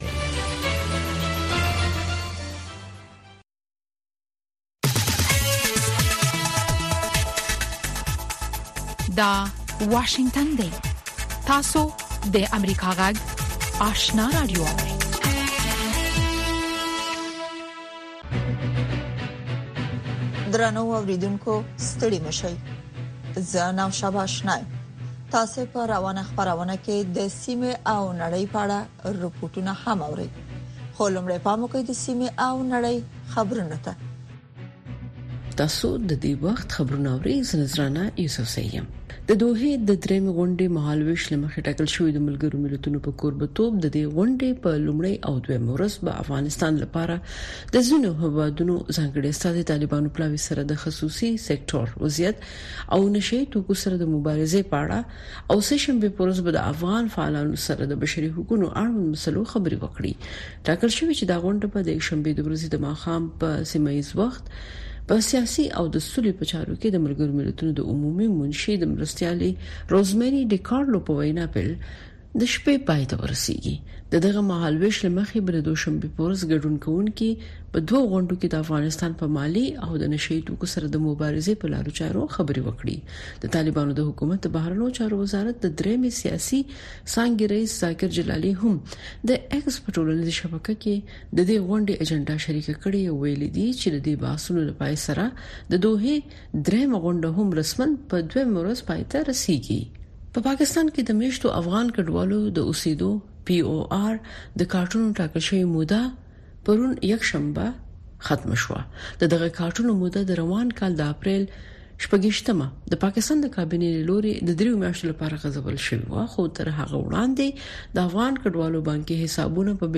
دویمه سهارنۍ خبري خپرونه
په سهارنۍ خپرونه کې د افغانستان او نړۍ تازه خبرونه، څیړنیز رپوټونه او د افغانستان او نړۍ د تودو پیښو په هکله مرکې تاسو ته وړاندې کیږي.